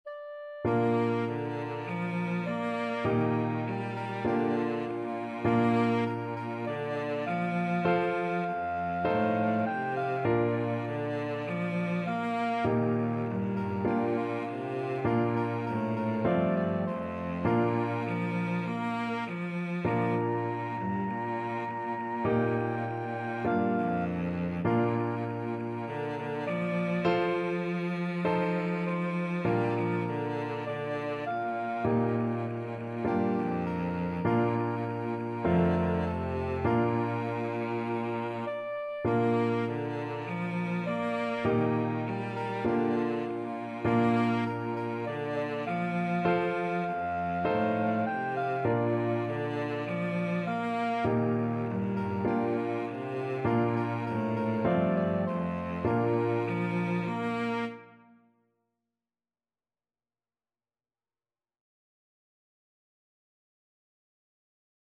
Soprano Saxophone
Cello
Piano
Bb major (Sounding Pitch) (View more Bb major Music for Flexible Ensemble and Piano - 2 Players and Piano )
4/4 (View more 4/4 Music)
Traditional (View more Traditional Flexible Ensemble and Piano - 2 Players and Piano Music)